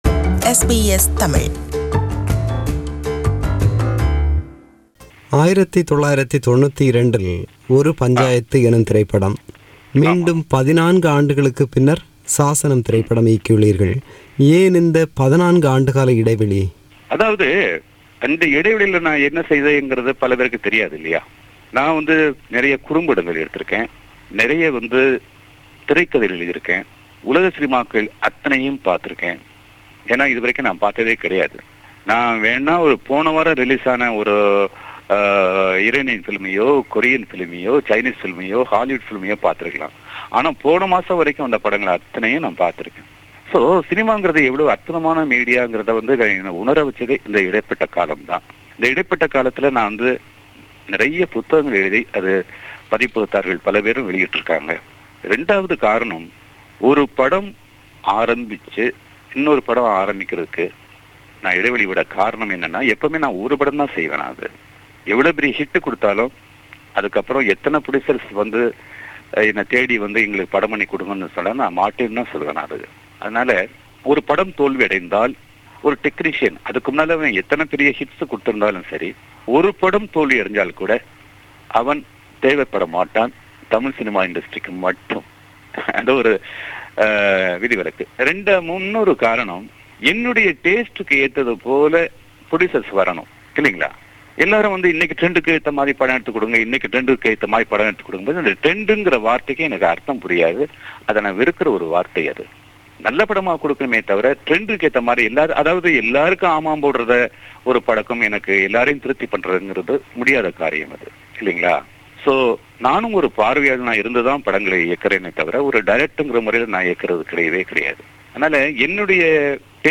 It's a rebroadcast of his interview with SBS Tamil.